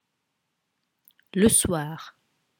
The listening will help you with the pronunciations.